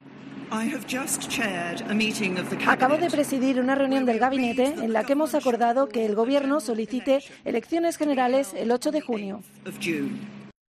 En una inesperada declaración en su residencia oficial de Downing Street, May ha comunicado su decisión de no esperar hasta 2020, el año previsto para la próxima cita electoral.